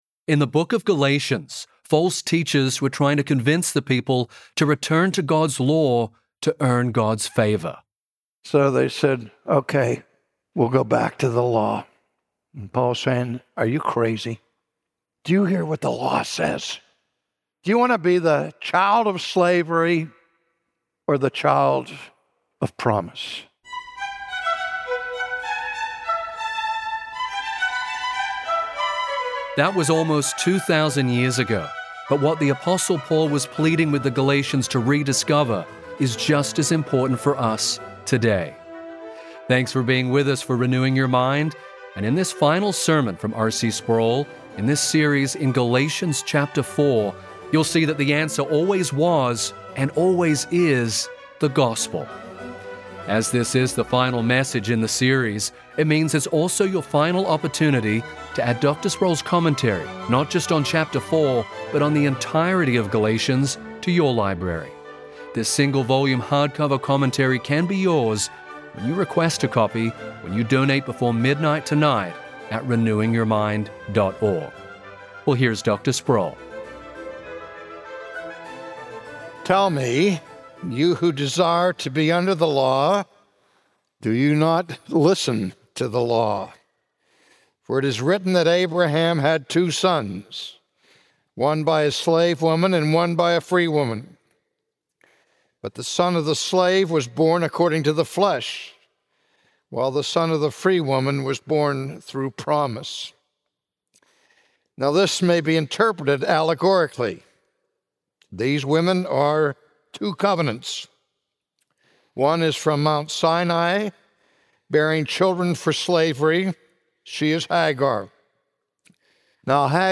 That's what Christians are doing whenever they turn to the law to earn God's favor. From his sermon series in Galatians, today R.C. Sproul reminds us why only the gospel can give us freedom from guilt.